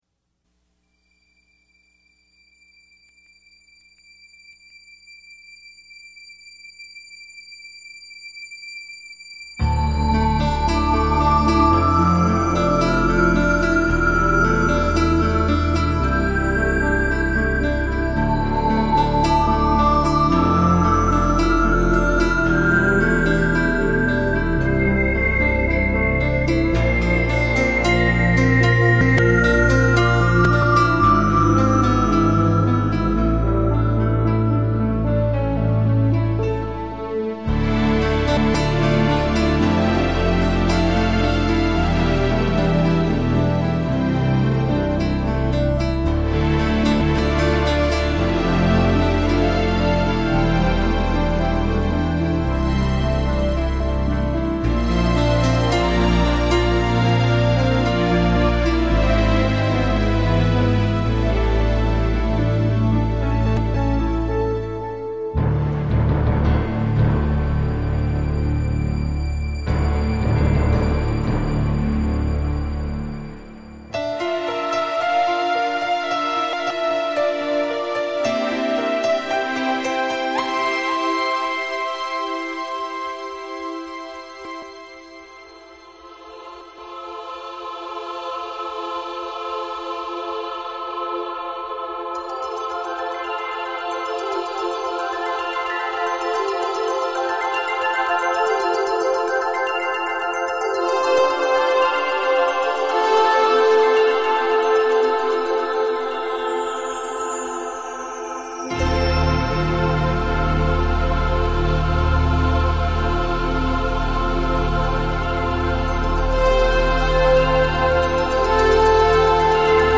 Esta canción es instrumental, y no tiene letra.